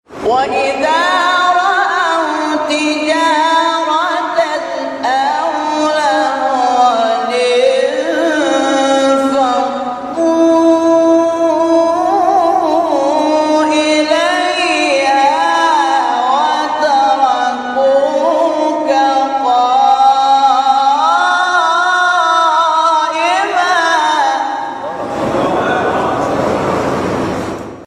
این فرازها به ترتیب در مقام‌های؛ نهاوند، عزام، بیات، حجاز و رست اجرا شده است.